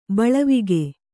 ♪ baḷavige